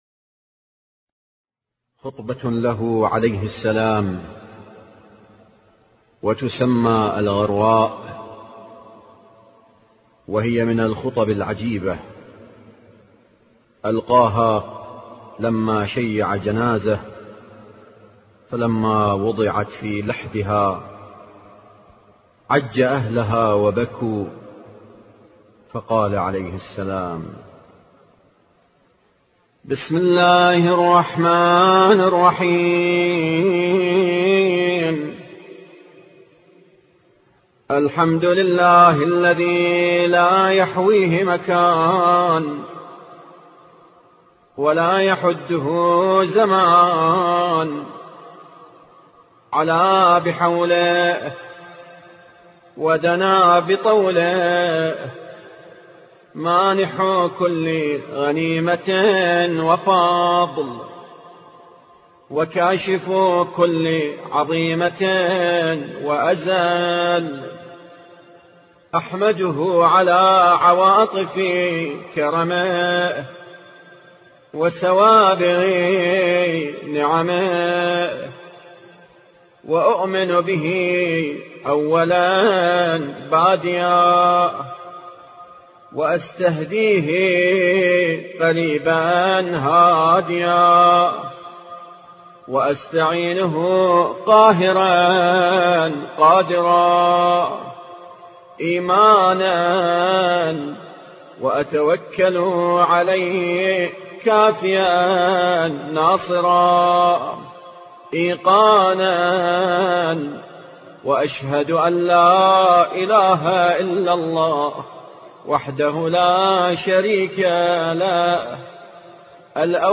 الخطبة الغراء